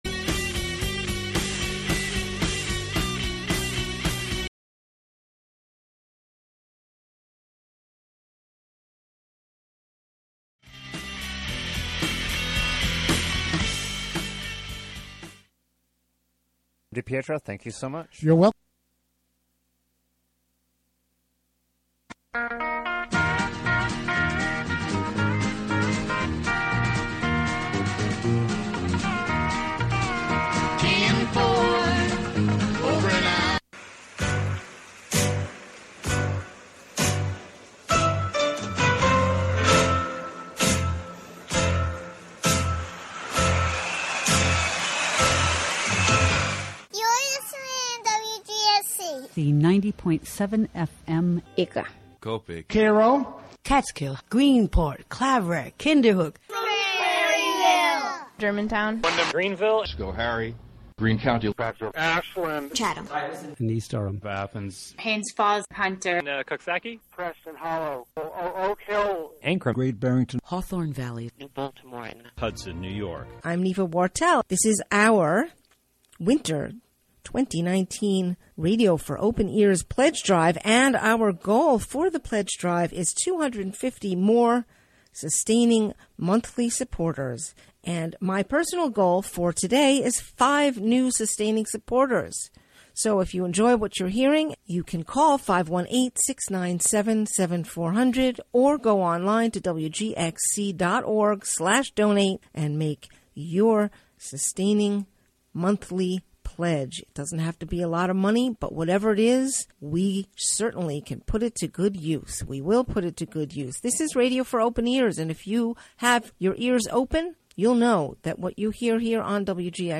and the "Forgotten Decades" music mix of lesser-played and/or misremembered songs from the '50s through the '90s. Broadcast live HiLo in Catskill.